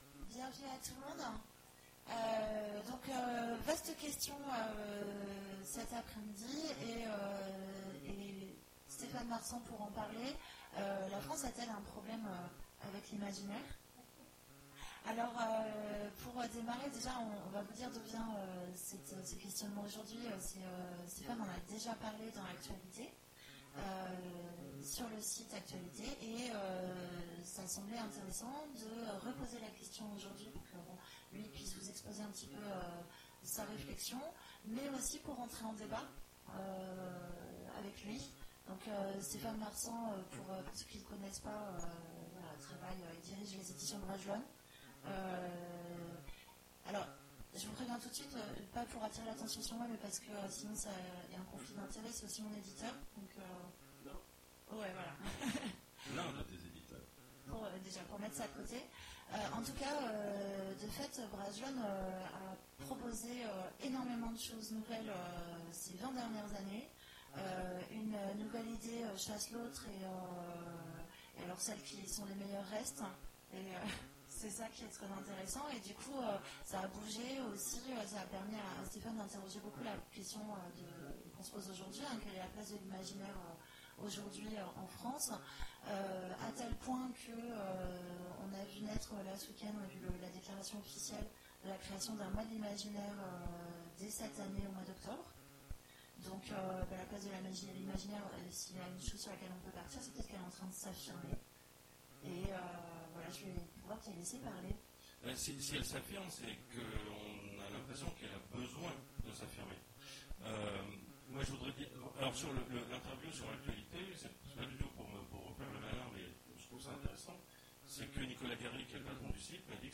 Imaginales 2017 : Conférence La France a-t-elle un problème… avec l'imaginaire ?